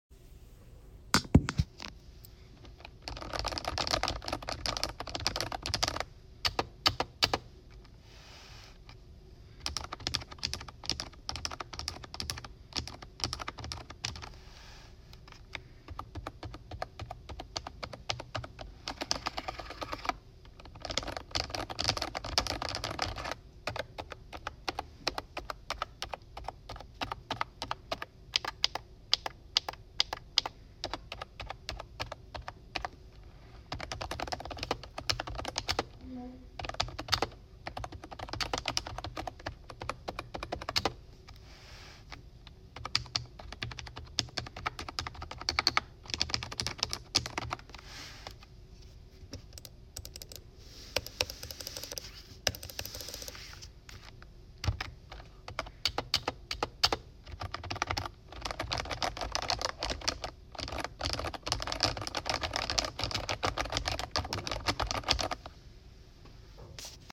typing sounds